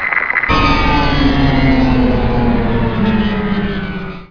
ui_shutdown.wav